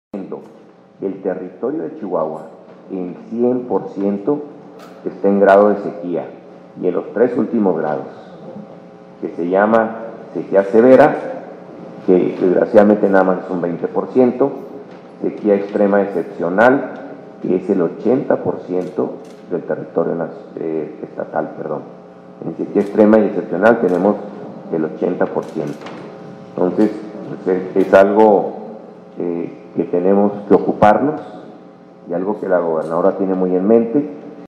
AUDIO: MARIO MATA CARRASCO, DIRECTOR EJECUTIVO DE LA JUNTA CENTRAL DE AGUA Y SANEAMIENTO (JCAS)